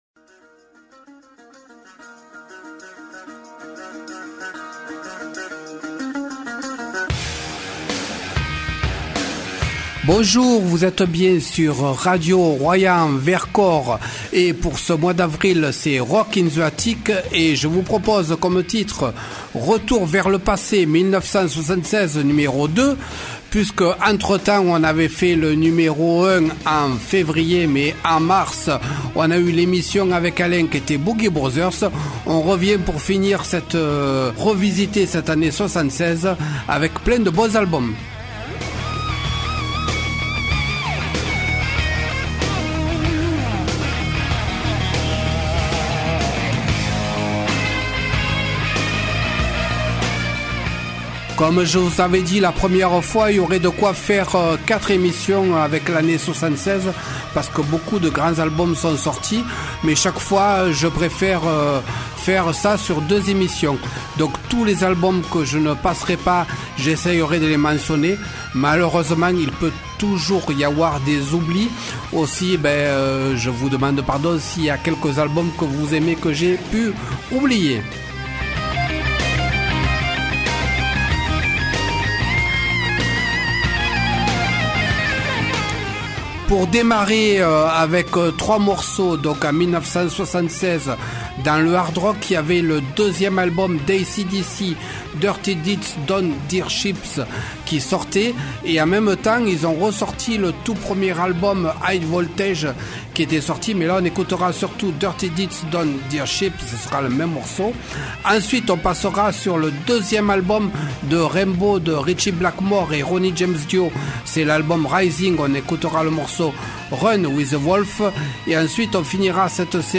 Du live et plusieurs genres musicaux : hard rock, pop rock anglaise et américaine, rock progressif, jazz rock, auteurs français, funk et pour finir reggae.